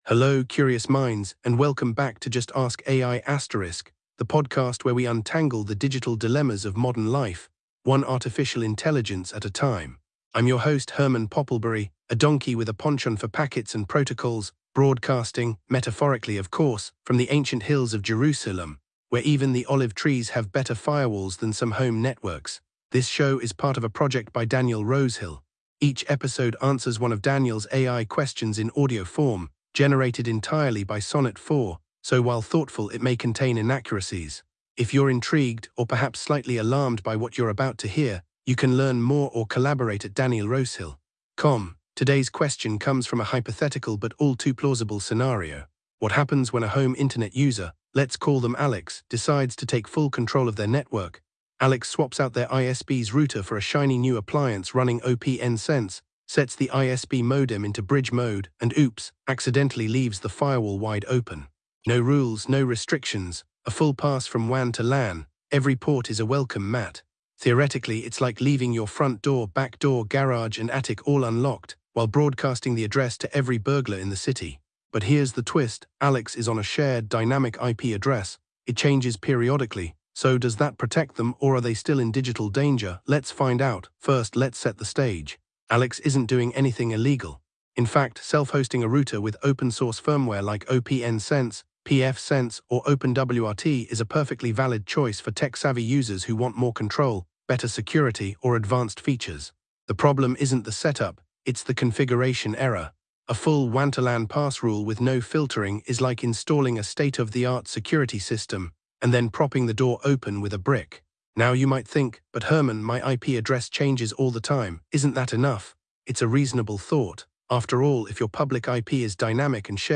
AI-Generated Content: This podcast is created using AI personas.